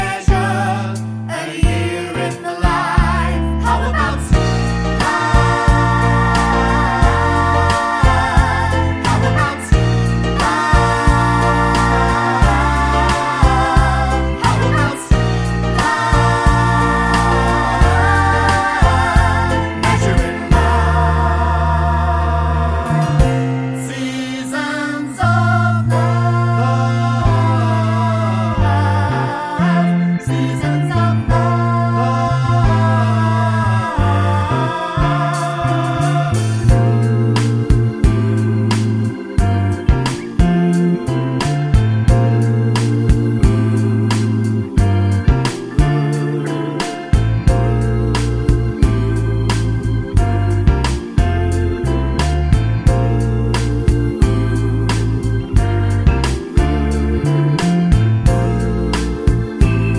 no Backing Vocals Musicals 2:50 Buy £1.50